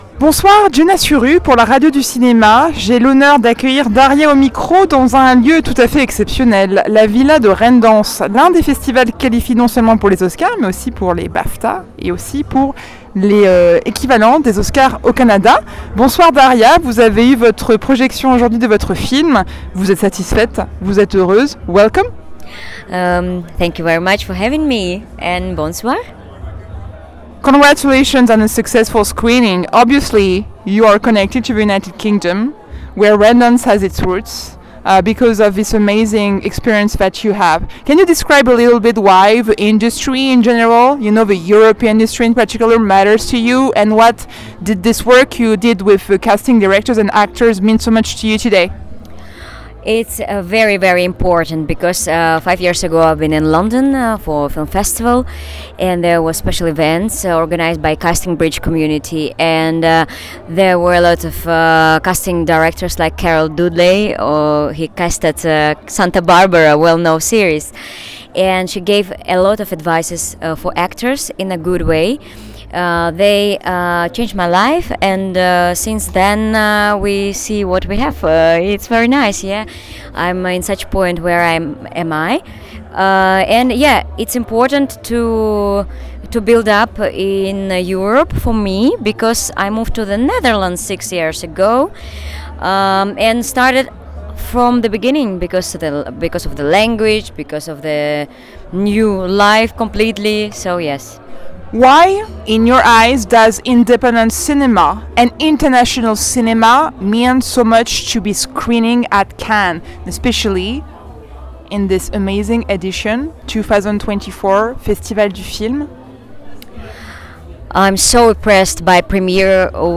In this interview, she discusses the importance of European cinema, the impact of characters, and her mission to empower women.
The interview concludes with well wishes for the rest of her Cannes experience.